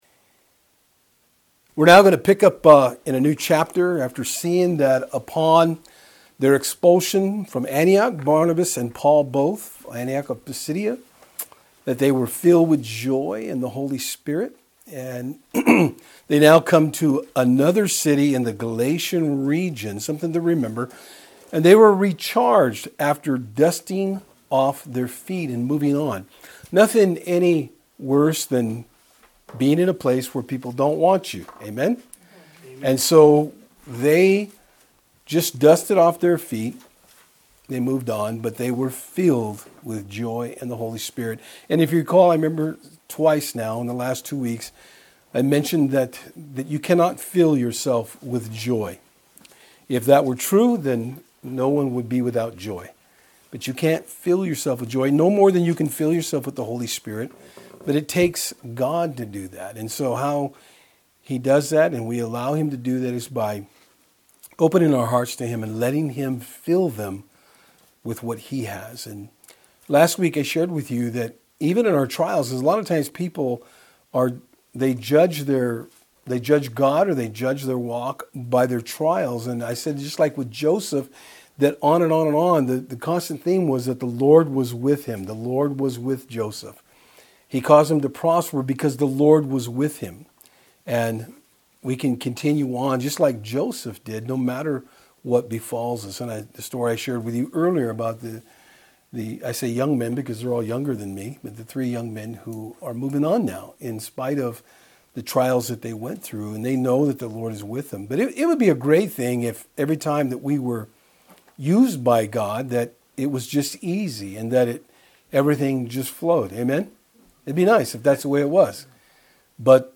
In our study tonight we will see how Paul & Barnabas were opposed every time they shared the gospel.